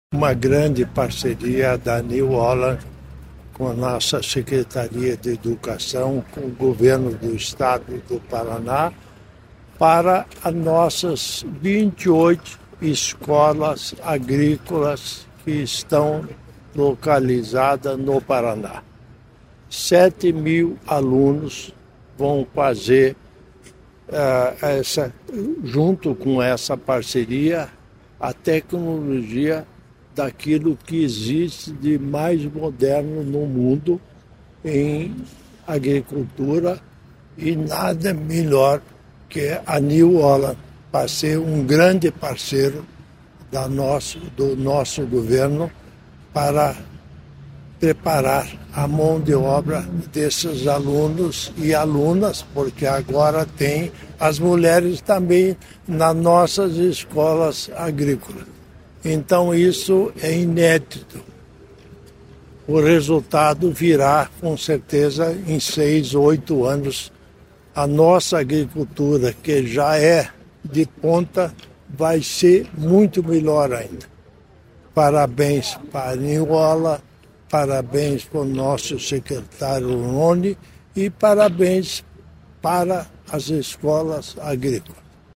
Sonora do vice-governador Darci Piana sobre a parceria da Educação com a New Holland para colégios agrícolas